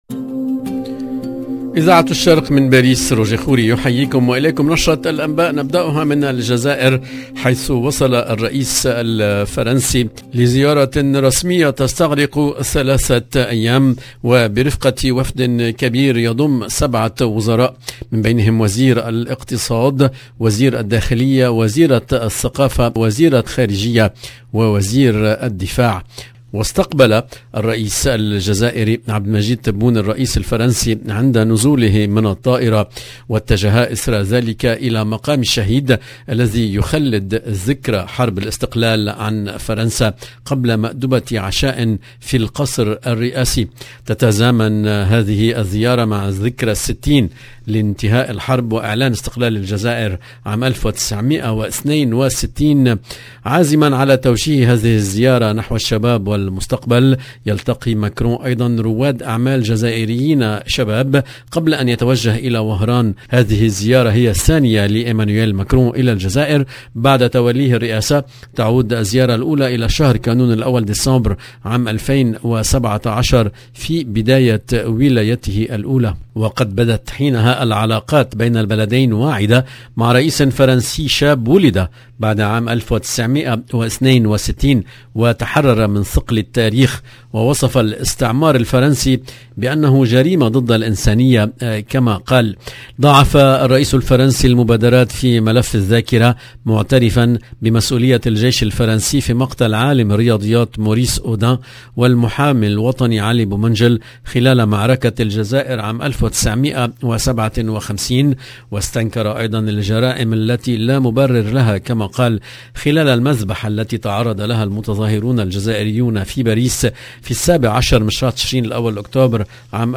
EDITION DU JOURNAL DU SOIR EN LANGUE ARABE DU 25/8/2022